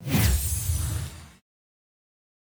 UI_Glyph_Out.ogg